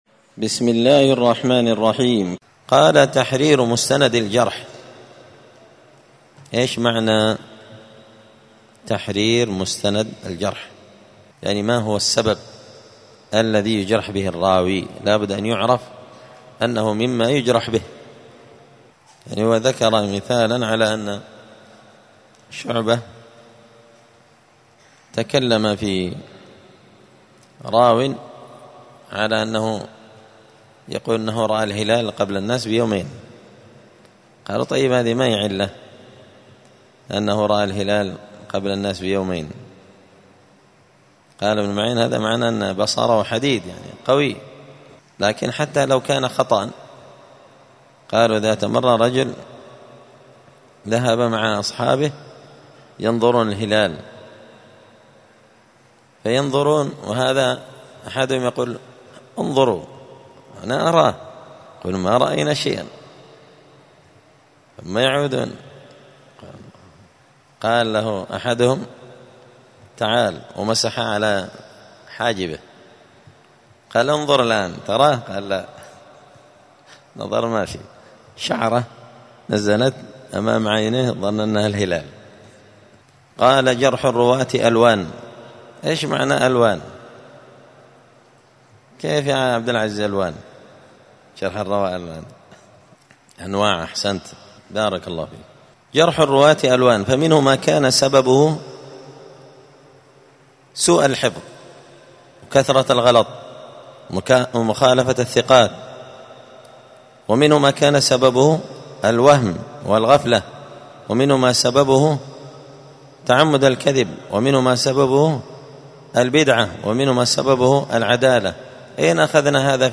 *الدرس الثامن والعشرون (28) تحرير مستند الجرح*